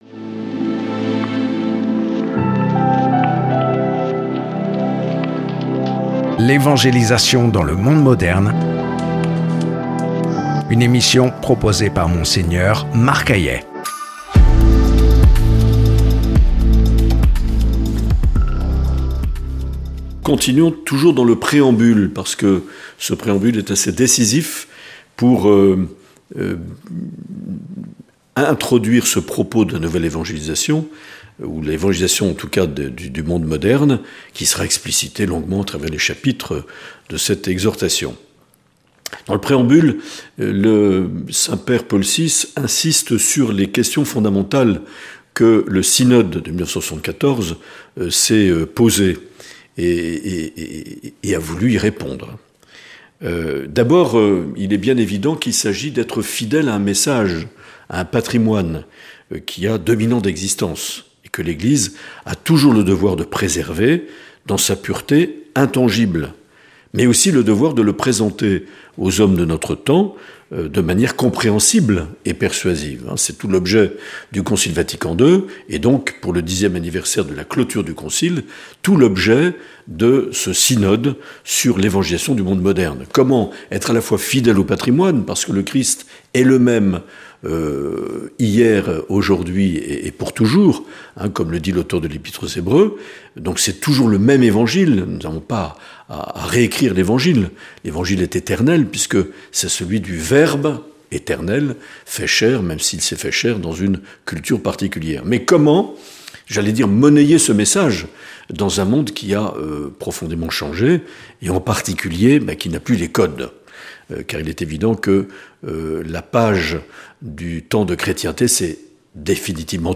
(Donibane Garaziko Herriko Etxean grabatua 2024. Urriaren 12an Nafar Lorialdiaren hilabetea kari – Zabalik elkarteak antolaturik).